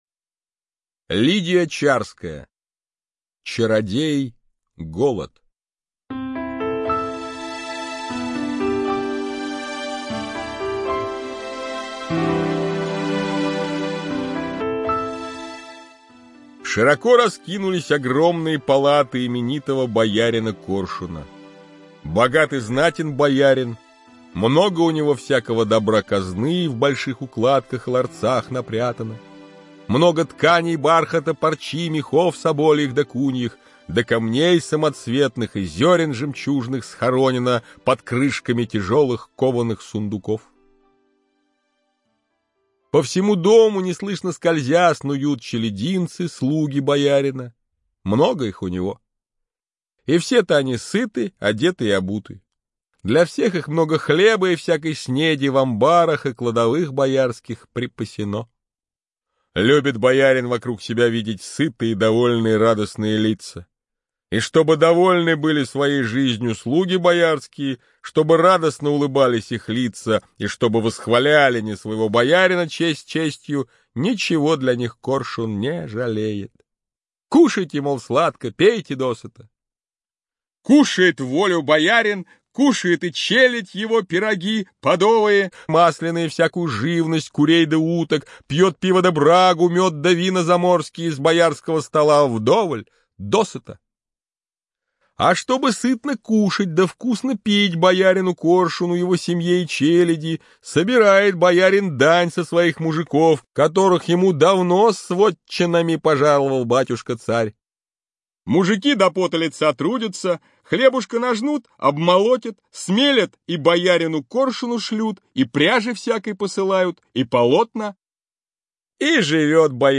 Чародей Голод - аудиосказка Чарской - слушать онлайн